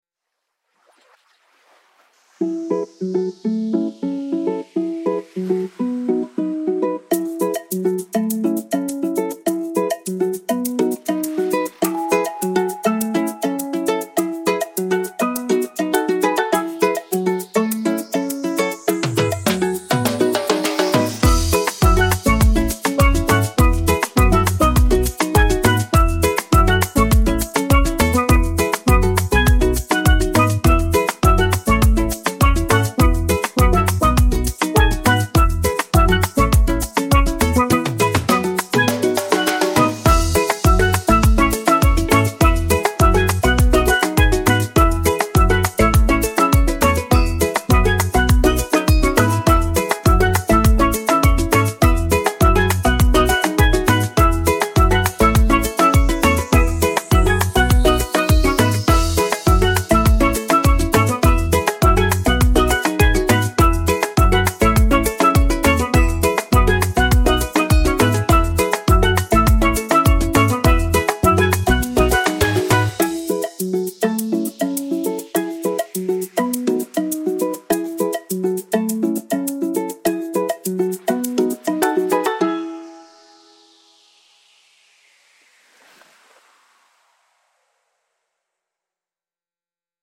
tropical birthday fiesta music with steel drums, maracas and sunny vibes